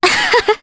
Scarlet-Vox_Happy2_Jp.wav